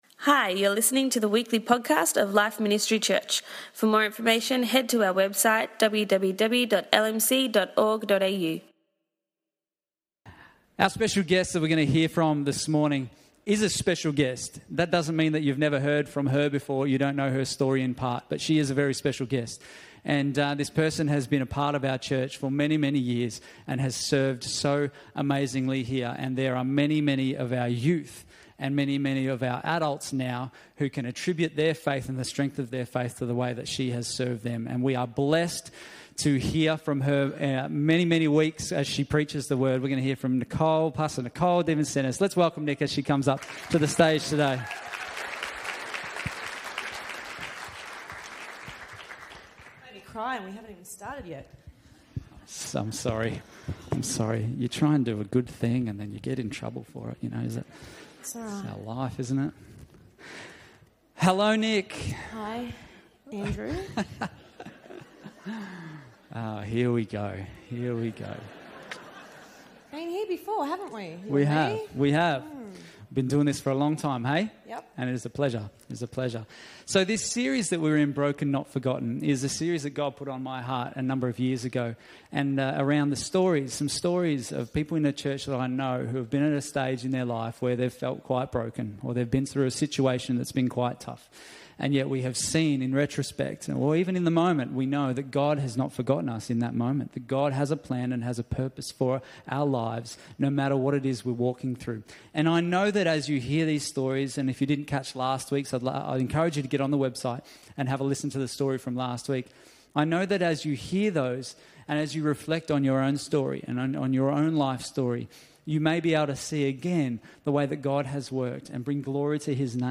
Broken Not Forgotten 2 | Life Ministry Church